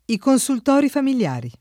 i konSult0ri familL#ri]; gli operatori dei consultori [